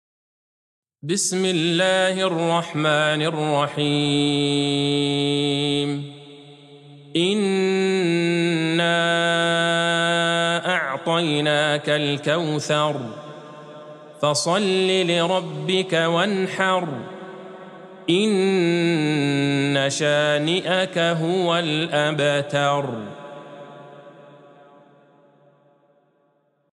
سورة الكوثر Surat Al-Kauthar | مصحف المقارئ القرآنية > الختمة المرتلة